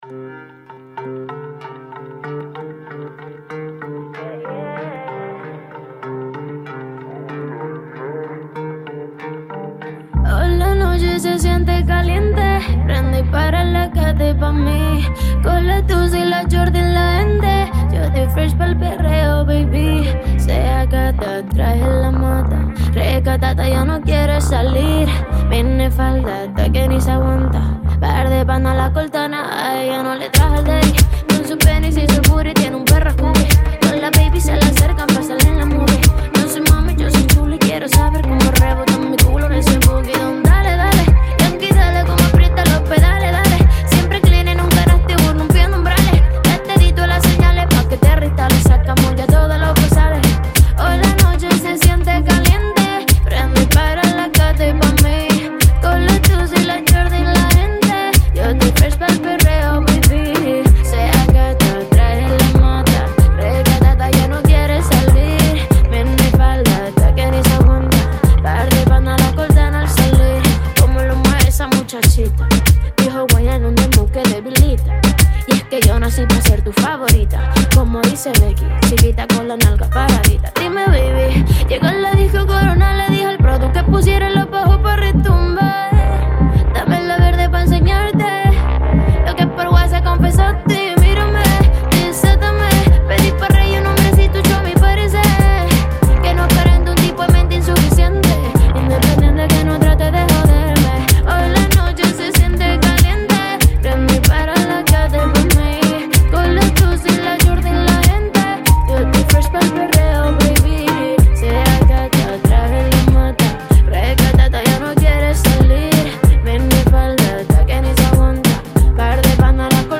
La cantante española